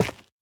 Minecraft Version Minecraft Version 1.21.5 Latest Release | Latest Snapshot 1.21.5 / assets / minecraft / sounds / block / dripstone / break3.ogg Compare With Compare With Latest Release | Latest Snapshot